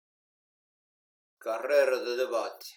Prononcer "La Carrère", "La Carrèro"...